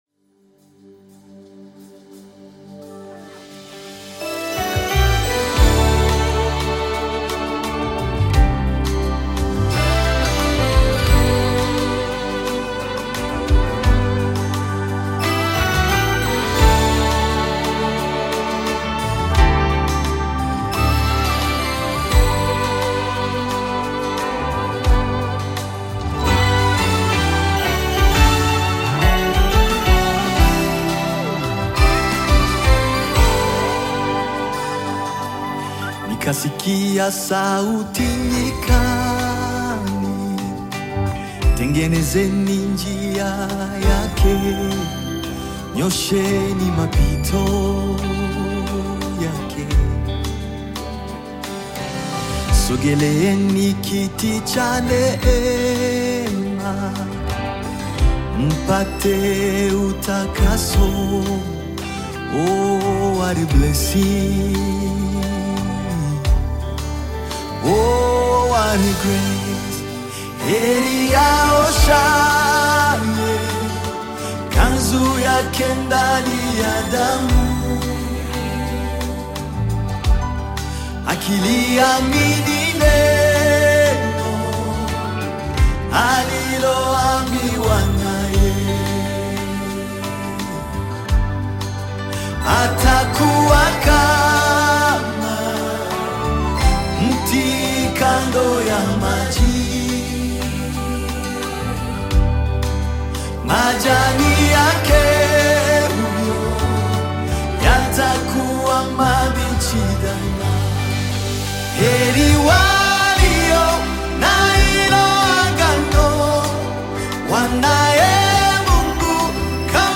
Nyimbo za Dini music
Gospel music track